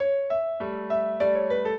piano
minuet10-10.wav